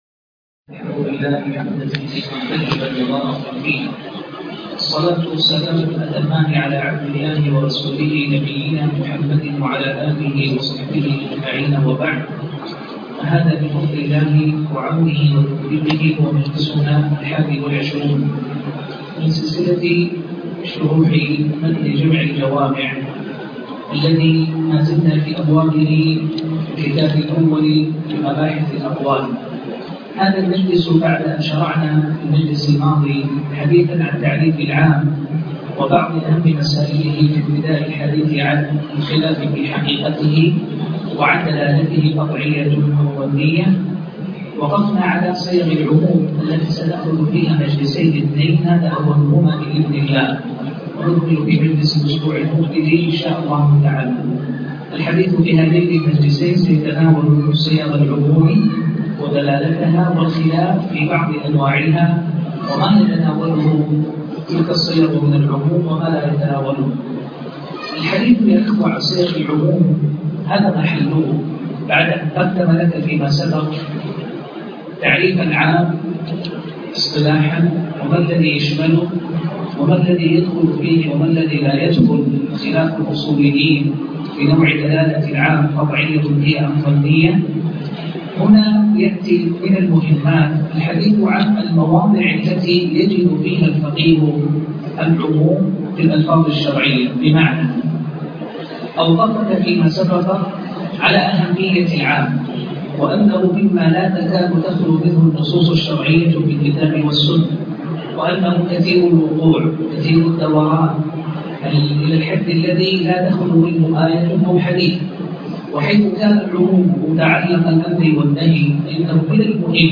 شرح جمع الجوامع الدرس 20